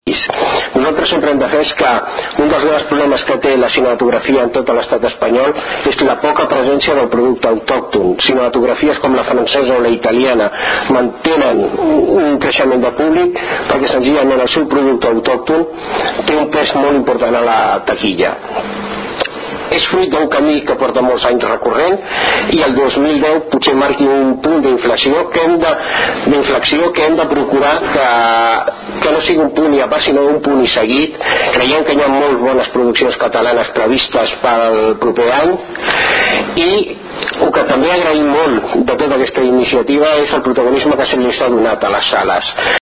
Intervencions durant la roda de premsa
Tall de veu